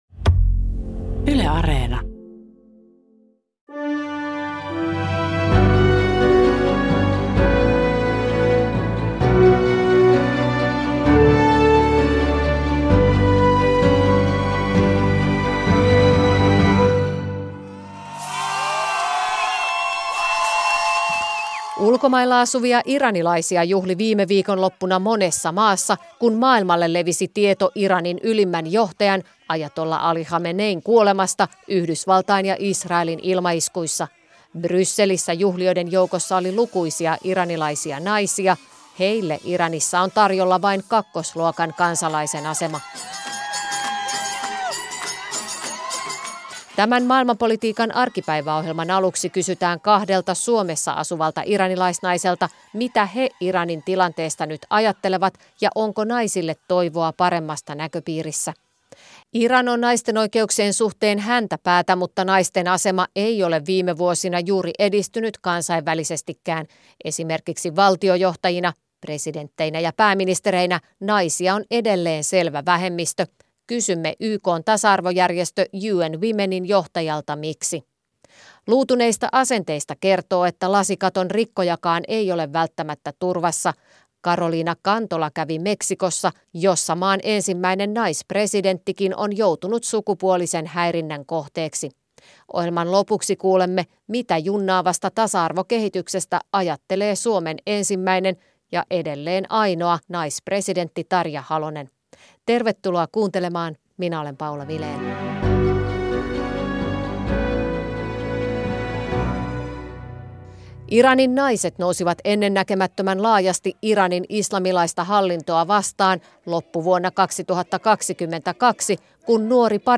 Ohjelmassa haastateltavien iranilaisten tasa-arvoaktivistien mukaan uskonto ja valtio pitäisikin erottaa tilanteen muuttamiseksi.
Ohjelmassa YK:n tasa-arvojärjestö UN Womenin apulaisjohtaja sekä Suomen ensimmäinen, ja tähän mennessä ainoa, naispresidentti Tarja Halonen pohtivat syitä hitaalle kehitykselle.
Ohjelmassa kuullaan raportti Meksikosta, jossa naisiin kohdistuva väkivalta on iso ongelma, eikä siltä ole ollut turvassa presidentti itsekään.